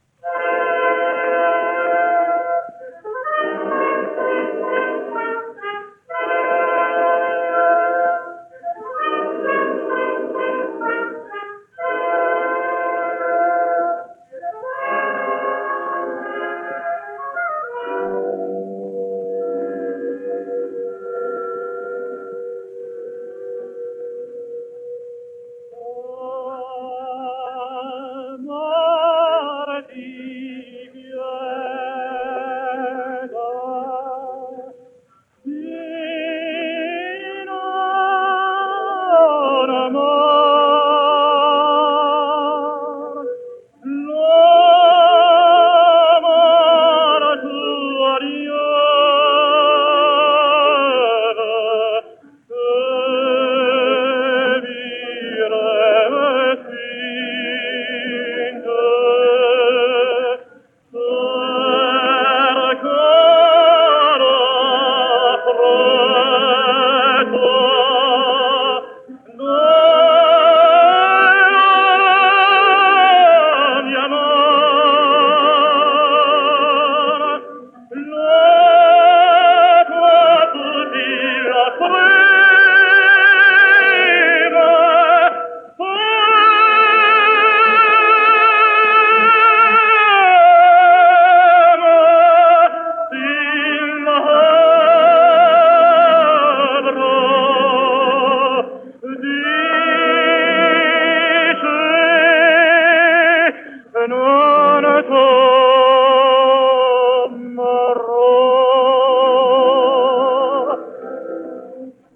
French Tenor.